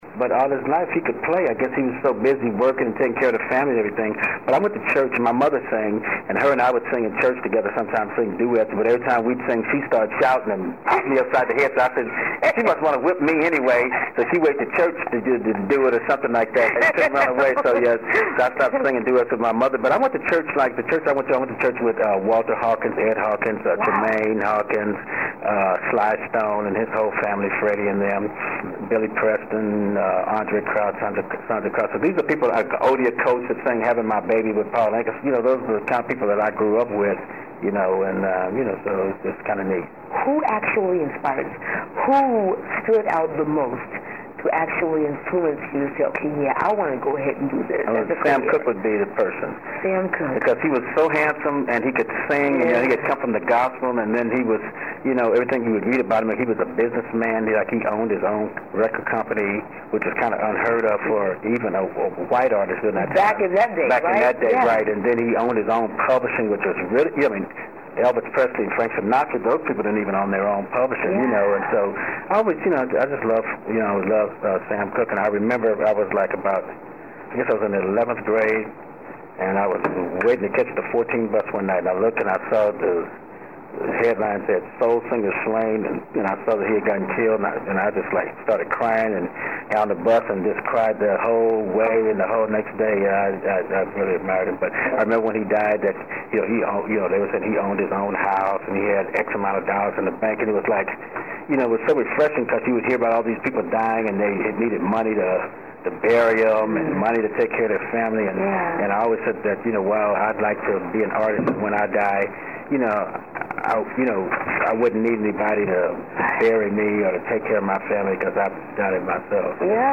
Celebrity Interviews